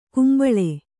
♪ kumbaḷe